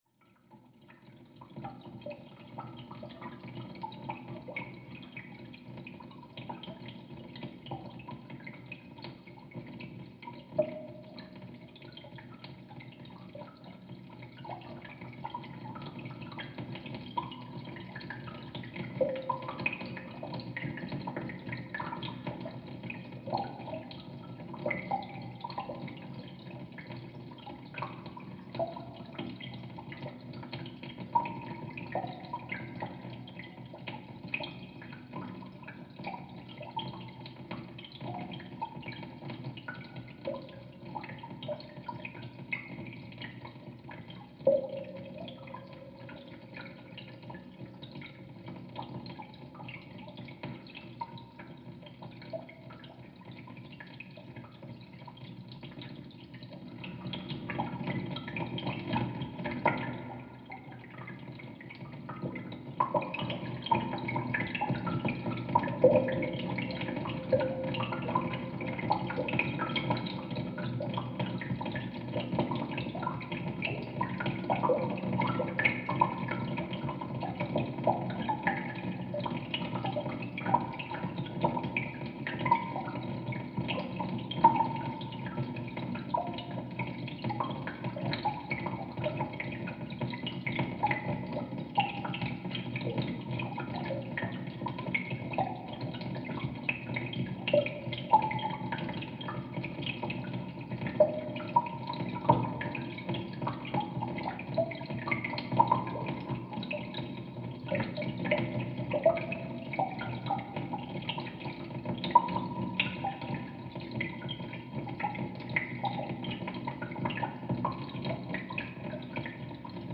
A hydrophone recording from the fountain at Piazza Campitelli, Rome